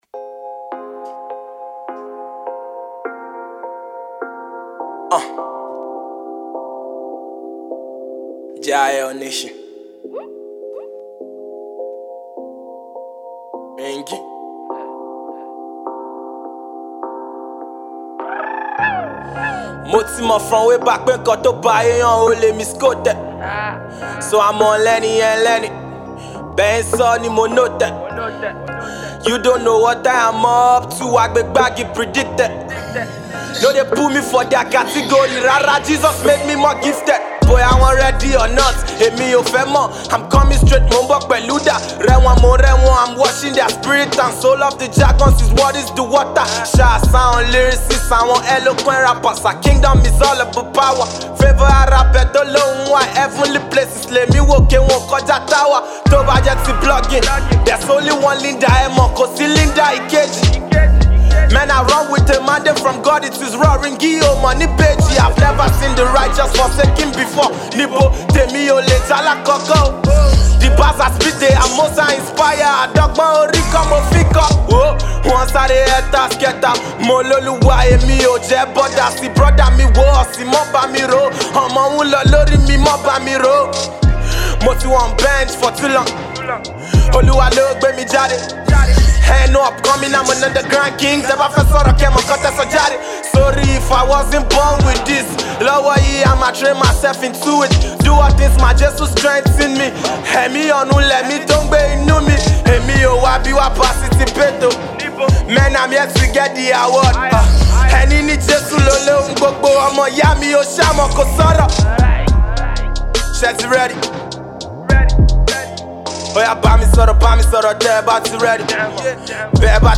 indigenous gospel rapper